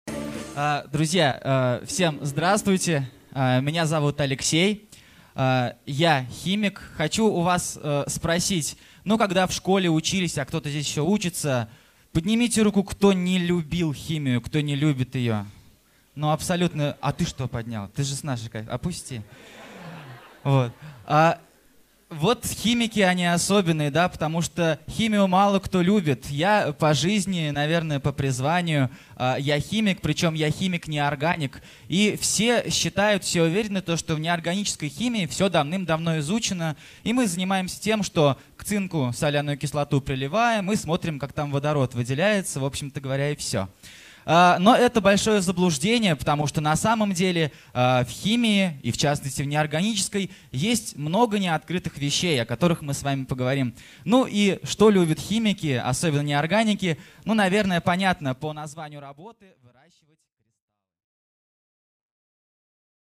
Аудиокнига В семье не без кристалла | Библиотека аудиокниг
Прослушать и бесплатно скачать фрагмент аудиокниги